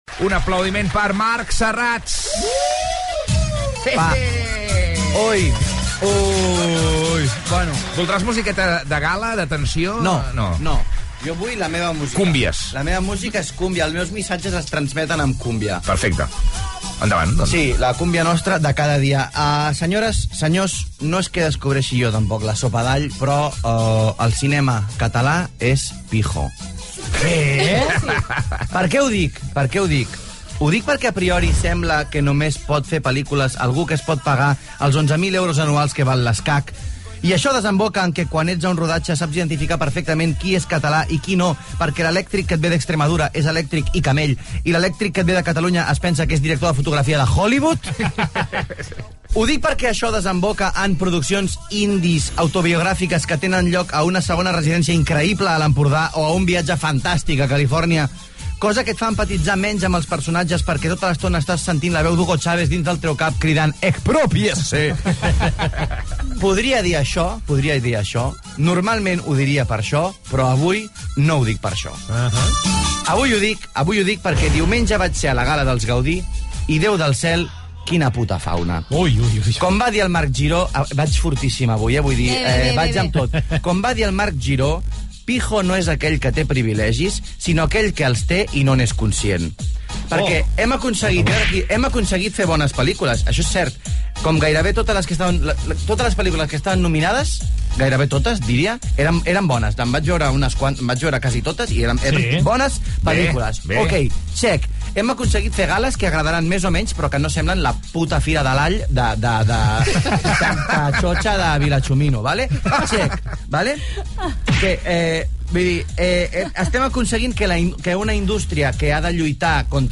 Crítica als professionals que surten de l'Escola de Cinema i Audiovisuals de Catalunya (ESCAC) i a l'actitud dels invitats a la gala de lliurament dels Premis Gaudí. Diàleg final de l'equip sobre el tema.
Entreteniment
FM